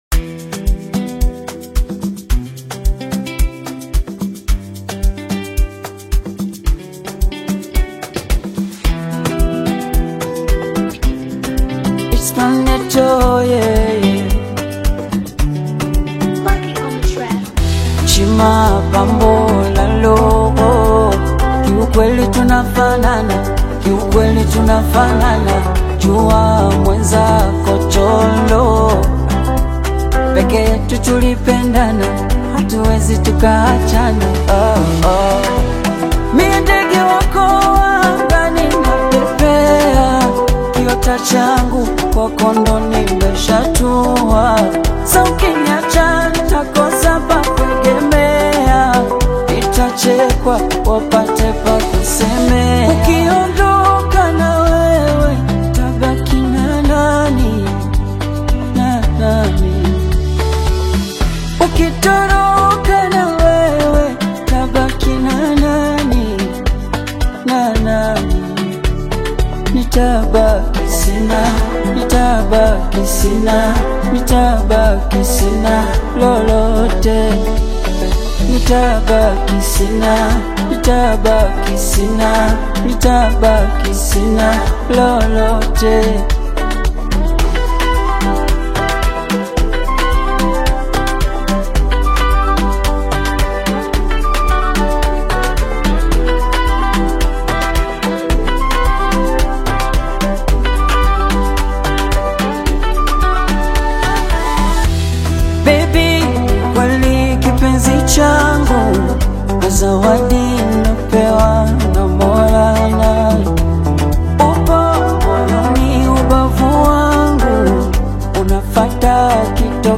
Afrobeat
heartfelt Afro-Pop/Bongo Flava ballad
warm, vibrant sound